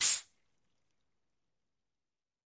SFX图书馆类项目 " 打开苏打水瓶的汽水声
描述：打开一瓶苏打水
Tag: 打开 饮料 苏打